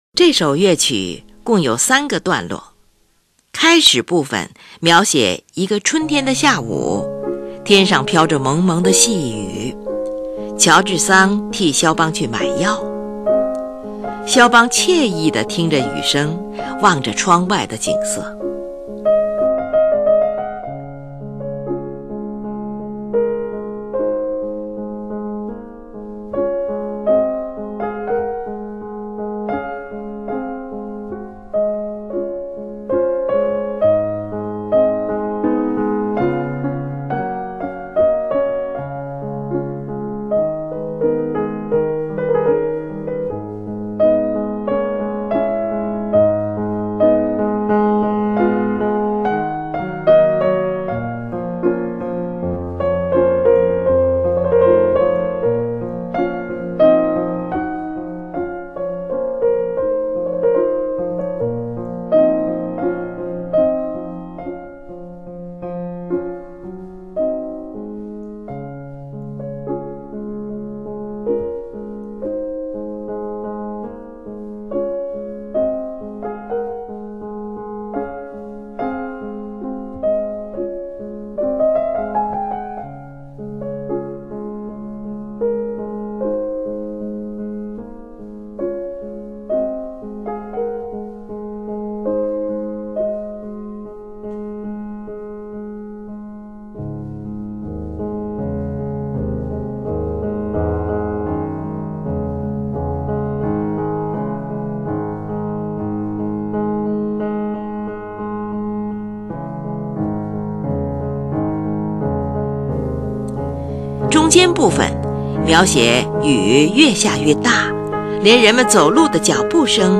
（乐曲的结尾）是描写雨声断断续续，慢慢消失，音乐也渐渐远去。